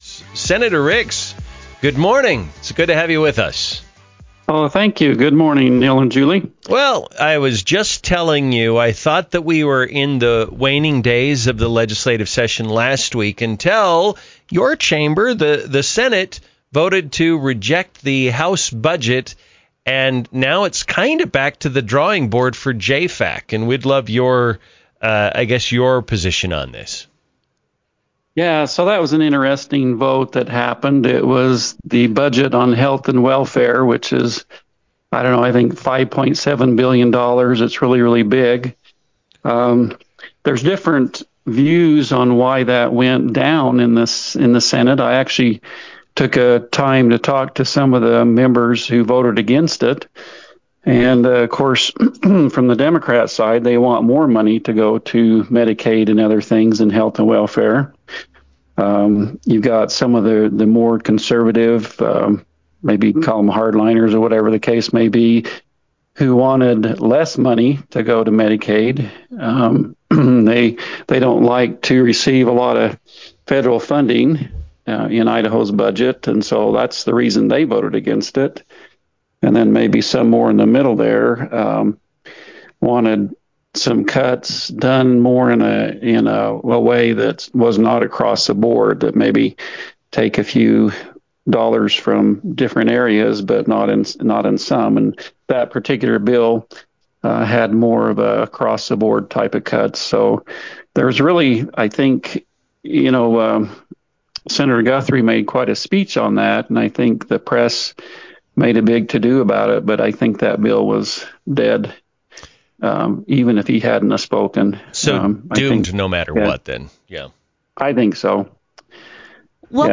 INTERVIEW: Sen. Doug Ricks on Budget Standoff, Legislation - Newstalk 107.9